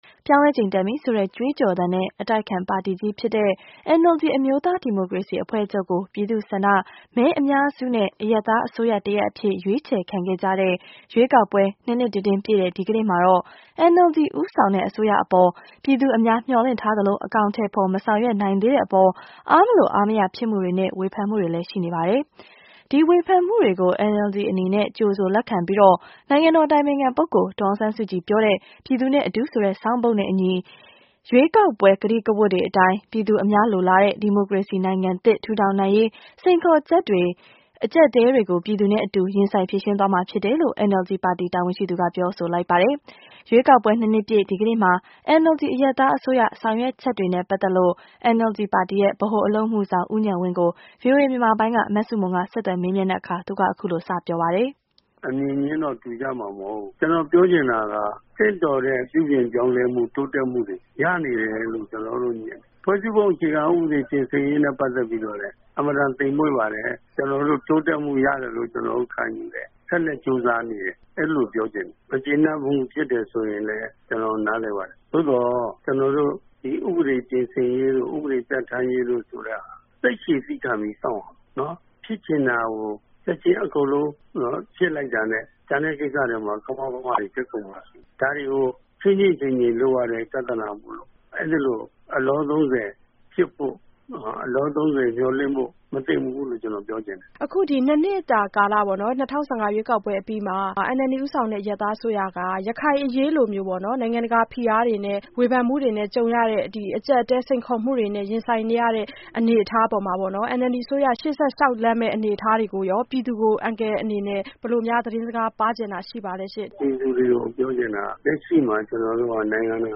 ရွေးကောက်ပွဲနှစ်လည် NLD ခေါင်းဆောင်နဲ့မေးမြန်းချက်